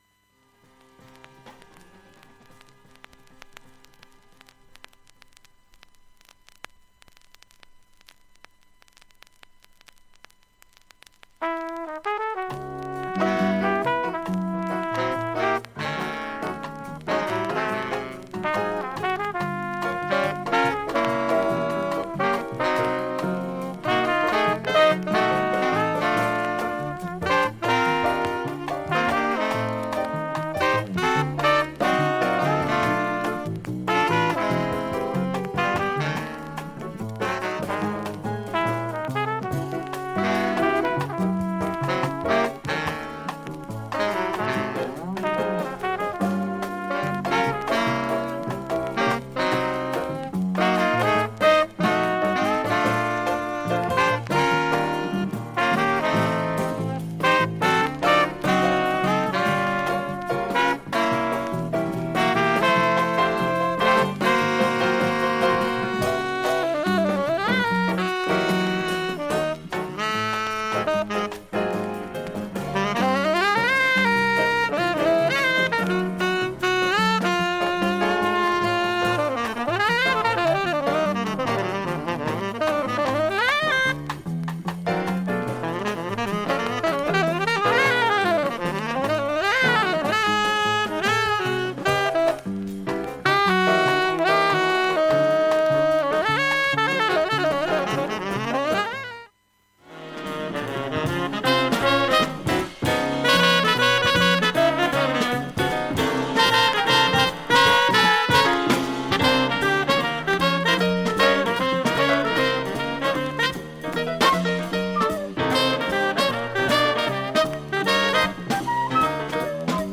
曲間にチリ音出ますが問題の無いレベルです。
普通に聴けます音質良好全曲試聴済み。
B-1中盤にかすかなプツが１７回出ます。
ネタのドラムブレイク入り